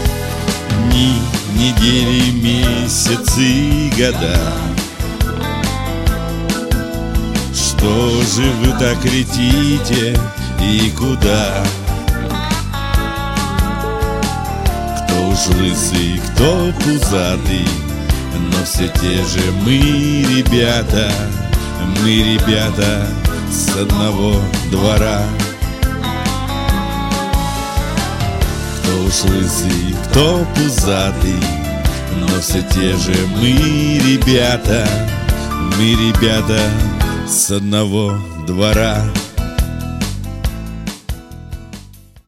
• Качество: 192, Stereo
душевные
спокойные
русский шансон
дворовые